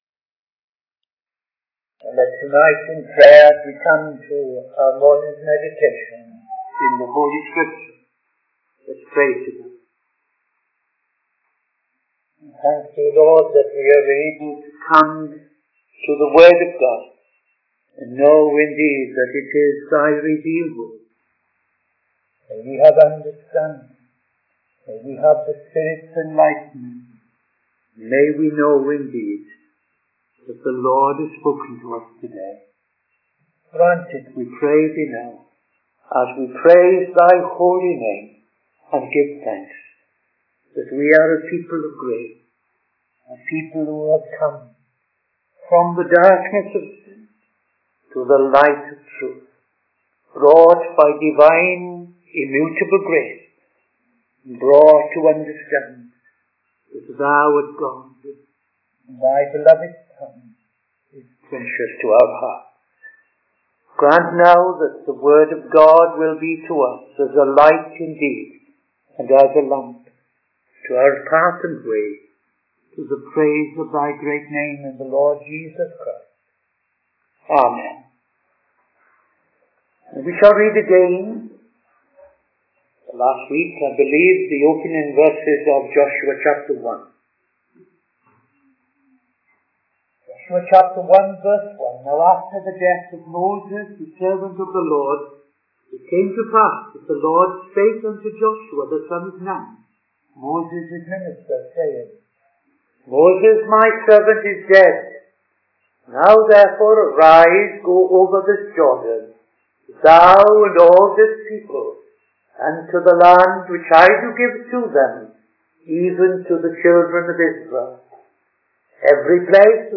Midday Sermon 21st September 2025